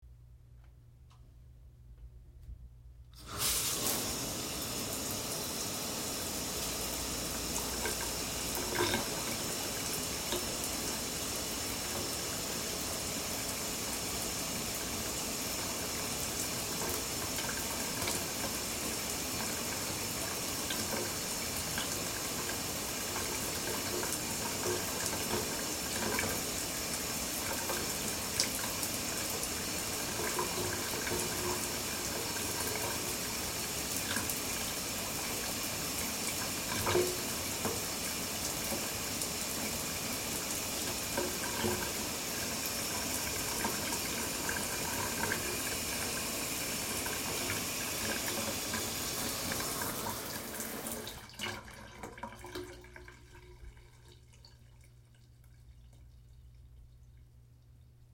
Download Shower sound effect for free.
Shower